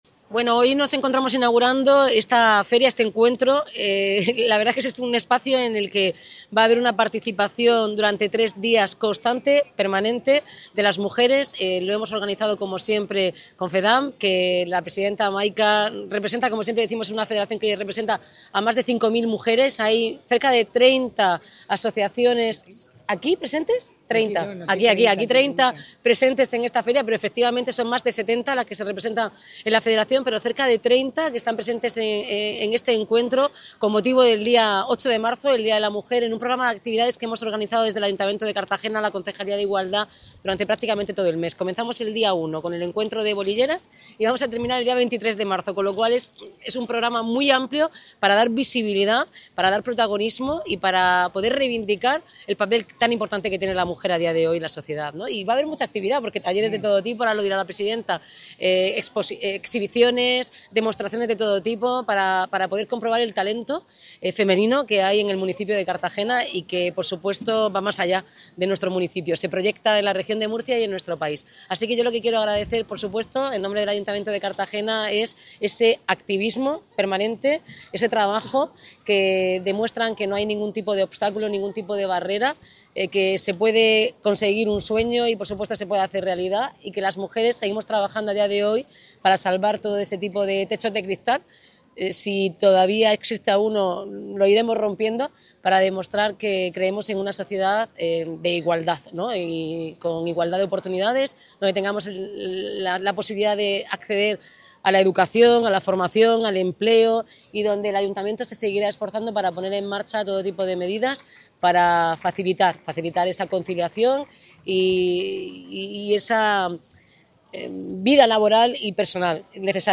La alcaldesa inaugura el encuentro que reúne a una treintena de entidades del municipio, en el marco de la programación del 8M que visibiliza el talento femenino y el compromiso del Ayuntamiento con la conciliación, el empleo y el deporte
La Plaza Héroes de Cavite acoge desde la tarde del viernes y hasta el domingo el Encuentro de Asociaciones de Mujeres, con una treintena de entidades que se reúnen en un espacio de convivencia, visibilización y participación activa impulsado por el Ayuntamiento en colaboración con la  Federación de Asociaciones de Mujeres Mediterráneo de Cartagena, que aglutina a 70 asociaciones y representa a más de 5.000 mujeres del municipio.